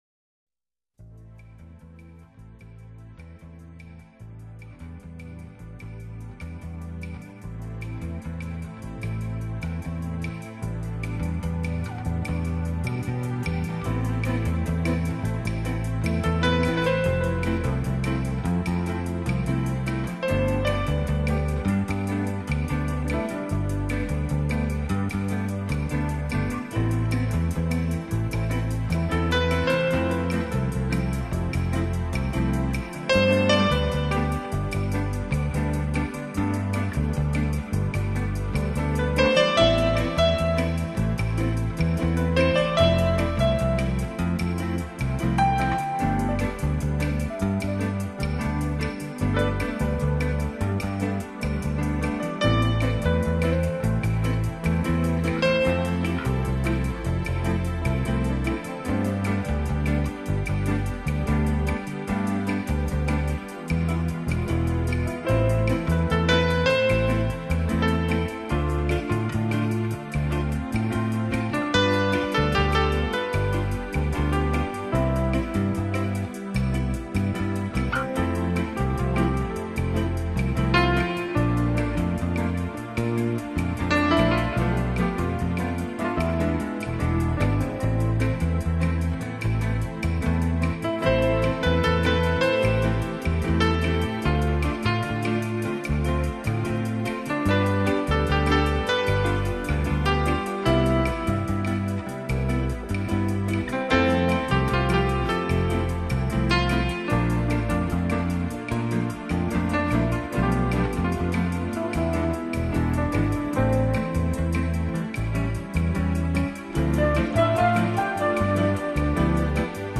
New Age
温柔地象微风细语般轻轻流过你的身体，洁净烦嚣。
旋律优美、柔丽的钢琴在深沉的笛子声及柔和的敲击衬托下更显演奏家